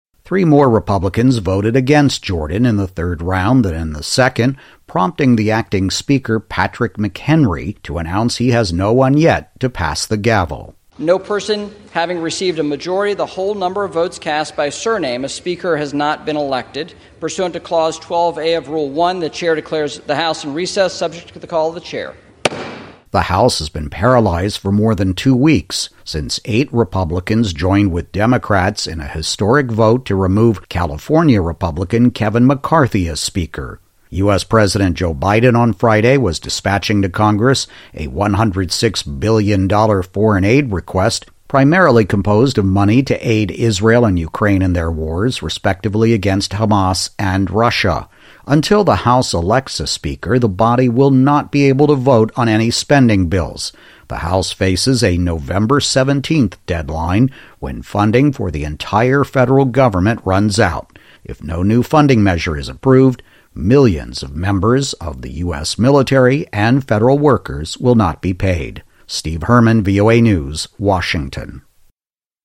The third time was not a charm for Ohio Republican Jim Jordan, hoping to become House speaker. Twenty-five Republicans voted today against the arch-conservative congressman, while all Democrats cast voice votes for their party’s leader in the chamber, Hakeem Jeffries of New York, meaning no candidate hit the threshold of 217 votes to be elected speaker. More in my VOA radio report here.